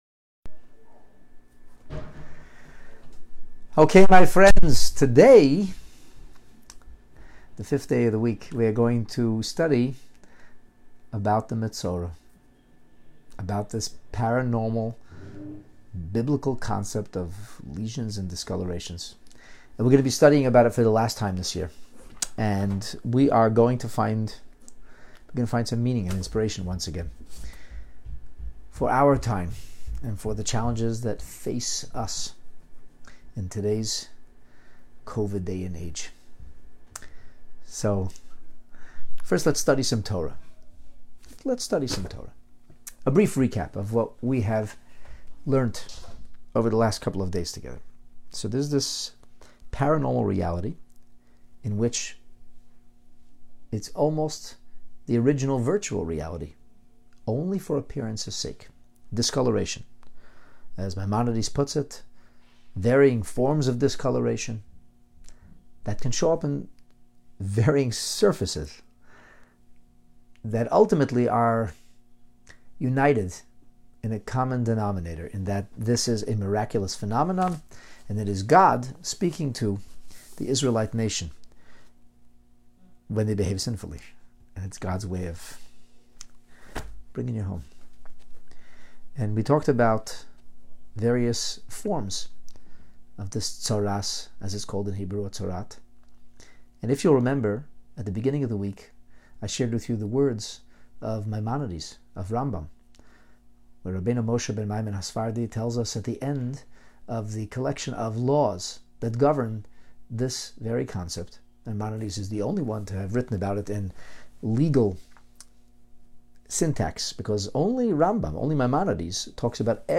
Teaching on Hidden Tzaarat Treasures: Golden Foundations and Silver Linings!